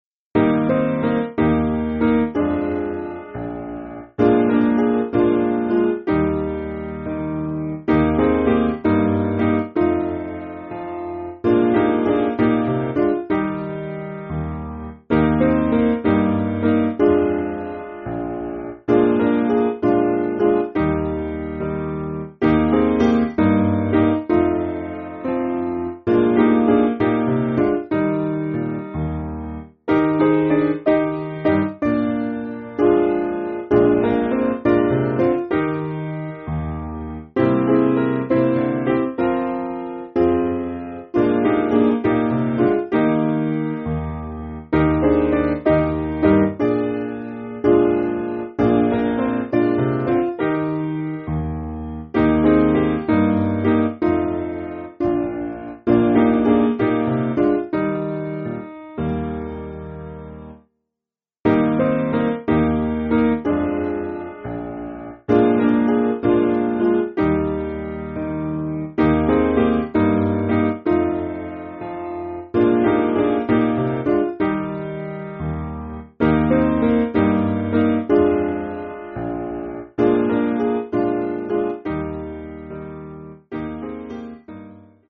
Simple Piano
(CM)   5/Eb